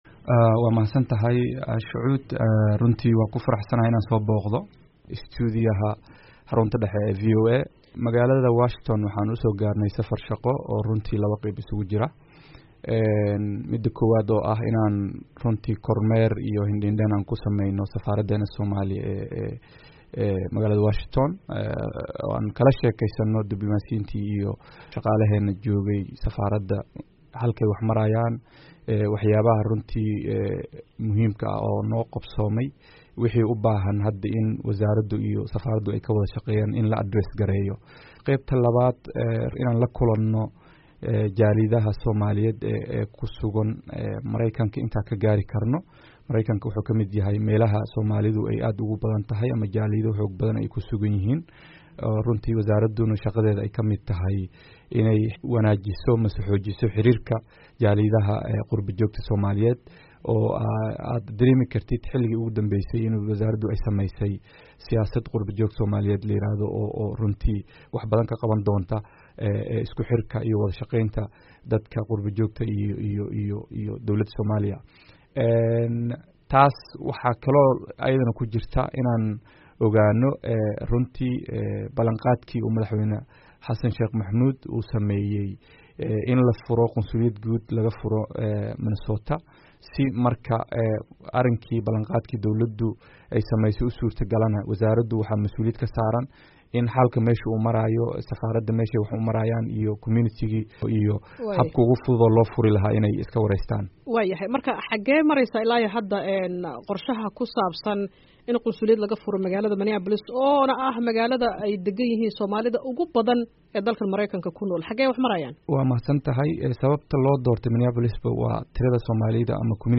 Wareysi: Soomaaliya oo qunsuliyad ka fureysa Minneapolis
Wareysiga wasiir ku xigeenka arrimaha dibadda